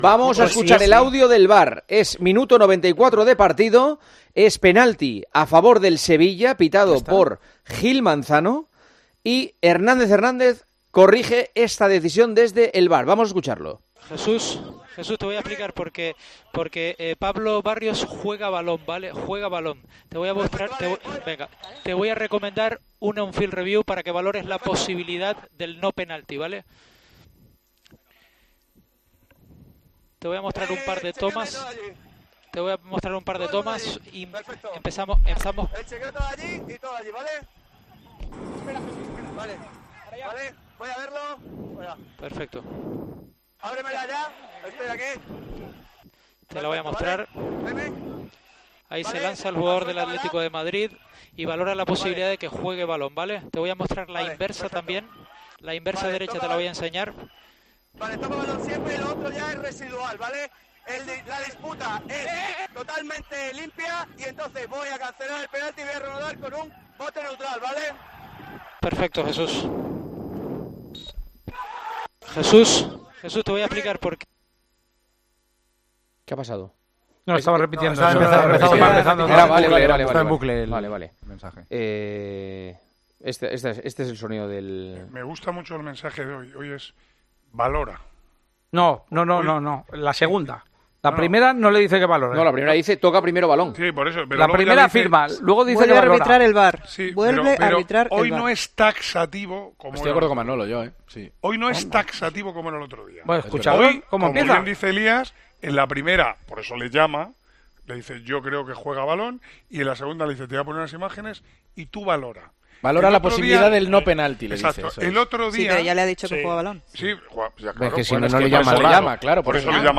Del Atlético de Madrid - Sevilla se hizo pública la conversación entre Gil Manzano y Hernández Hernández de la última jugada, en la que Gil Manzano rectifica y no pita penalti.
ESCUCHA EL AUDIO DEL VAR DE LA POLÉMICA JUGADA DEL ATLÉTICO DE MADRID - SEVILLA Y SU ANÁLISIS EN EL PARTIDAZO DE COPE